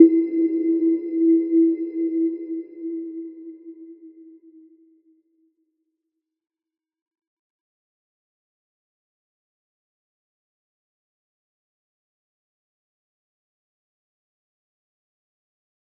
Little-Pluck-E4-mf.wav